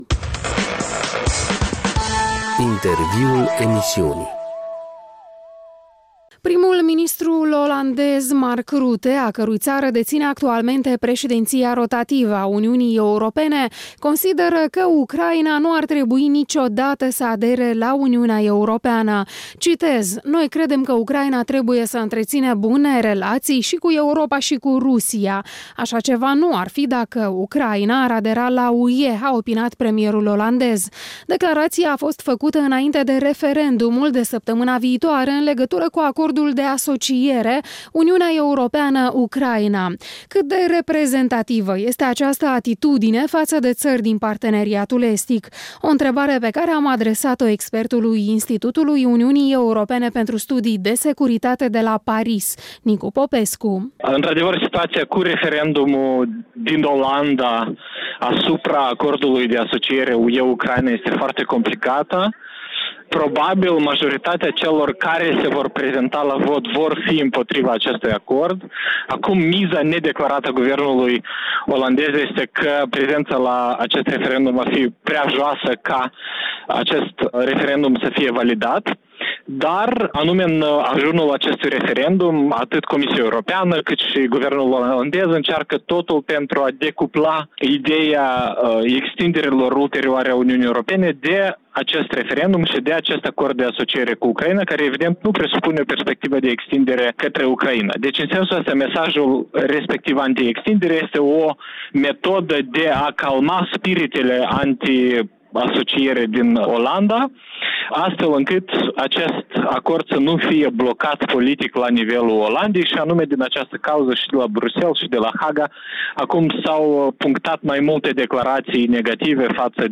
Un interviu cu expertul Institutului UE pentru Studii de Securitate de la Paris.